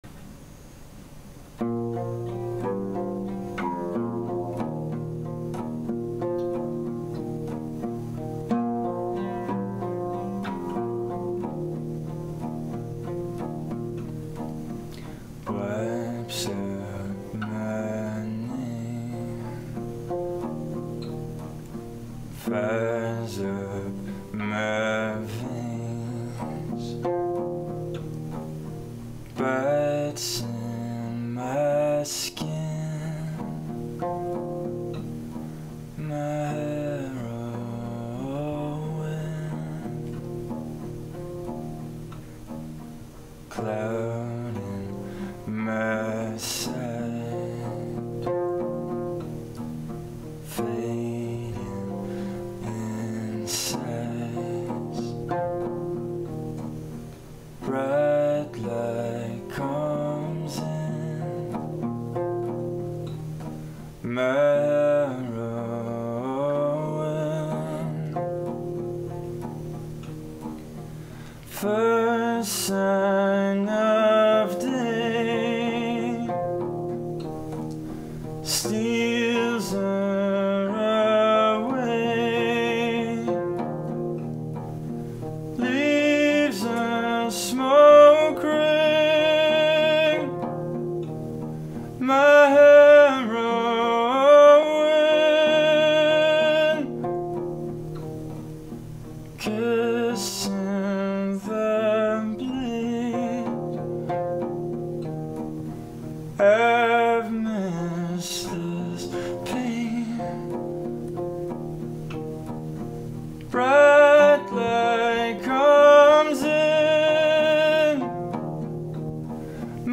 Indie Rock, Folk Pop, Acoustic